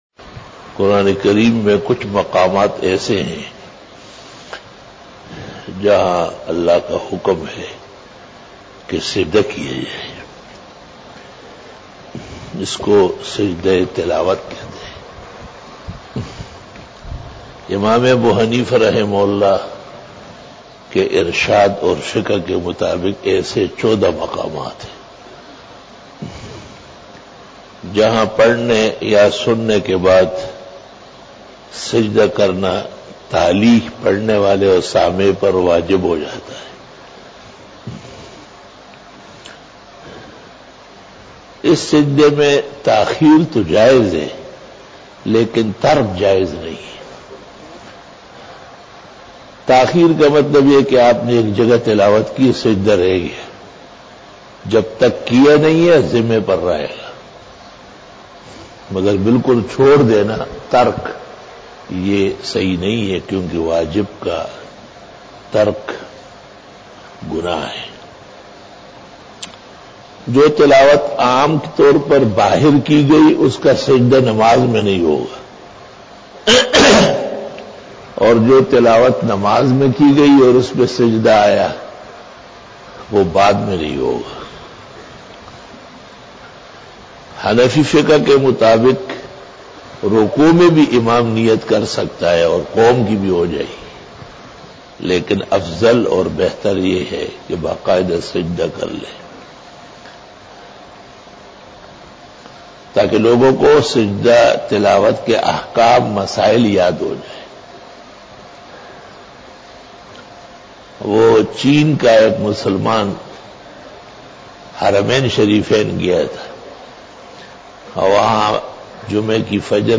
After Fajar Byan
بیان بعد نماز فجر